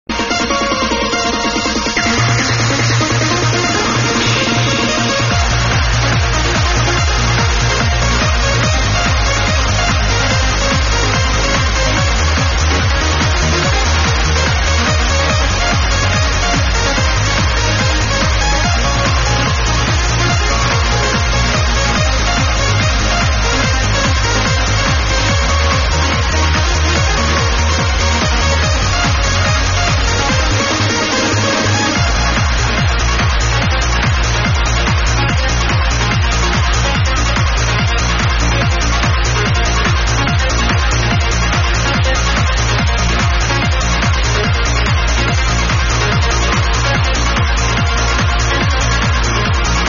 Question 5 HardTrance ID tracks (track4)